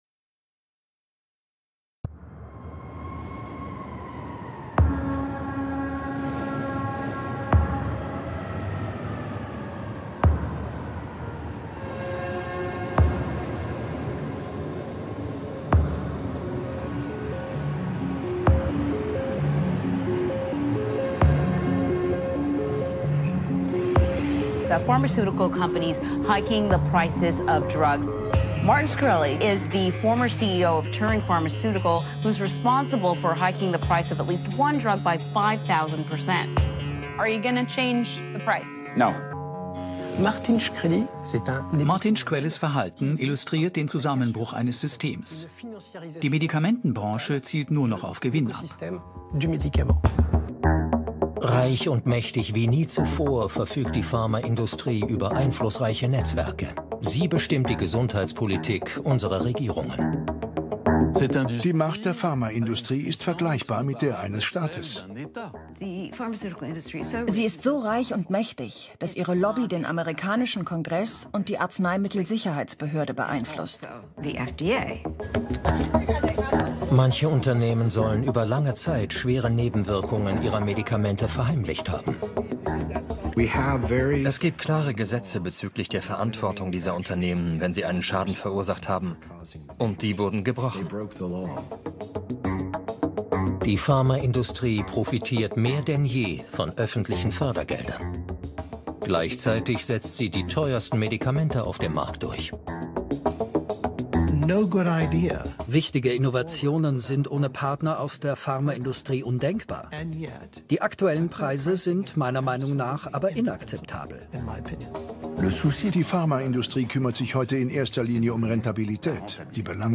Sie zeigt durch Stellungnahmen von Patienten, Whistleblowern und Anwälten sowie Analysen von Medizinern, ehemaligen Ministern und Vertretern der Pharmaindustrie die Ökonomisierung des Arzneimittelsektors.
Dokumentarfilm von Claire Lasko und Luc Hermann (F 2018, 88 Min)